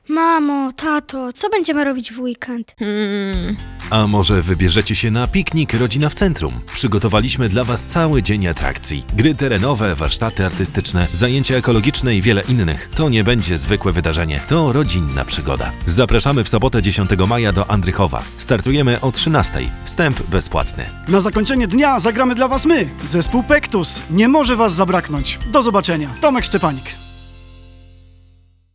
Nasz spot dźwiękowy: